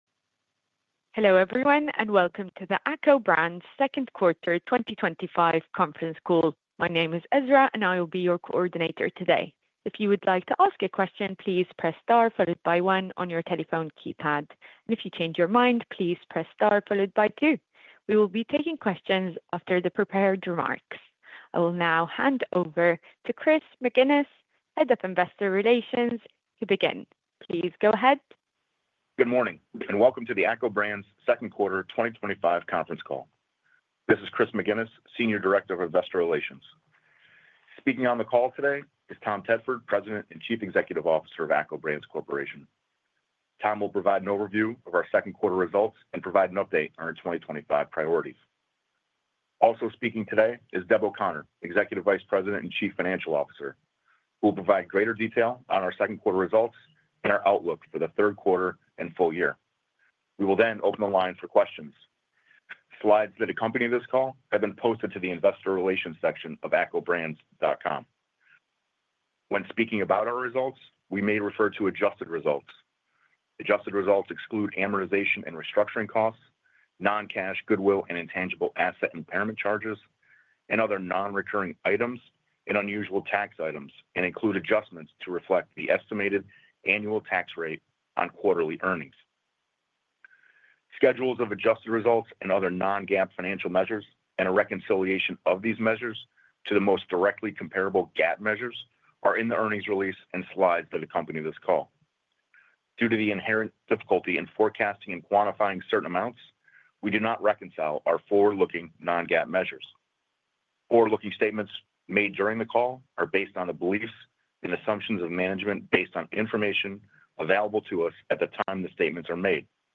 This recording is from the earnings call held on 1st August, 2025, in respect of the financial results for the quarter and six-months ended June 30, 2025.
ACCO-Brands-Q2-2025-Earnings-Call.mp3